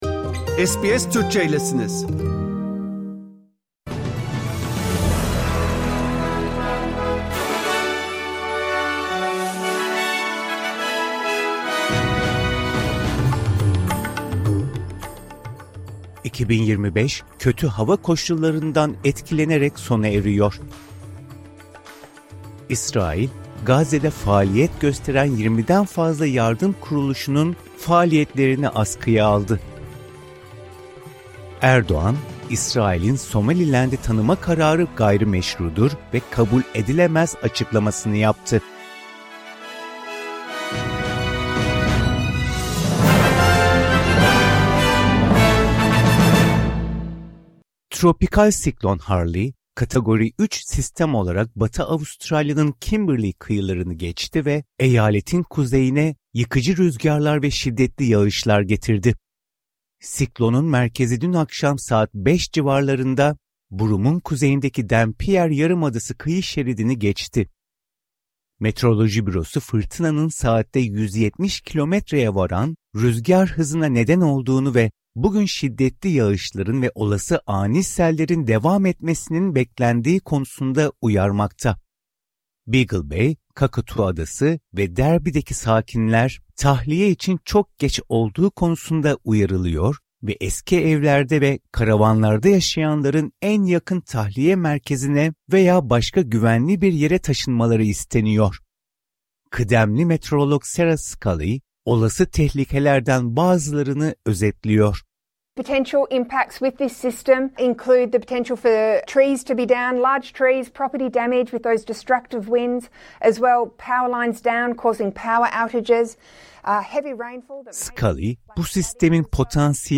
SBS Türkçe Haber Bülteni Source: SBS